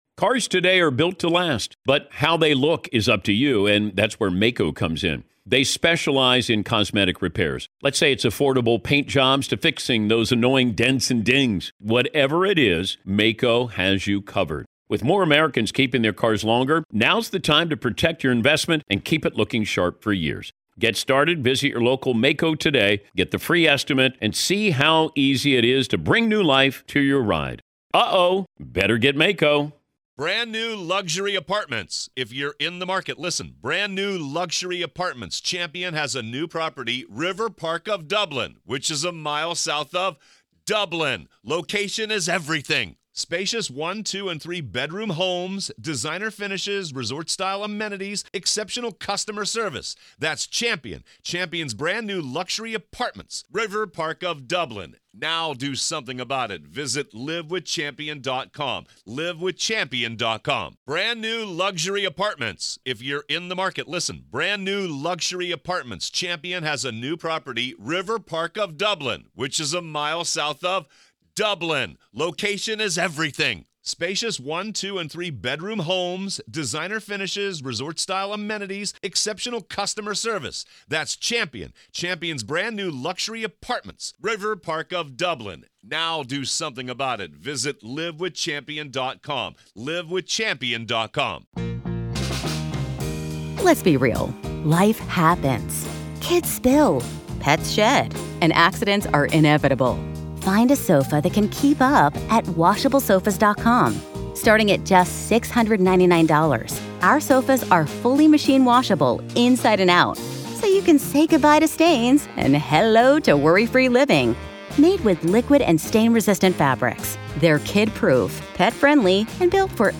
Alex Murdaugh Trial: Courtroom Coverage | Day 8, Part 6